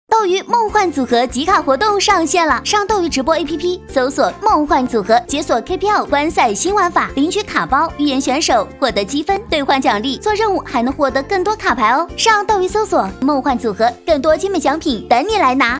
女41号-活动宣传配音-美食活力甜美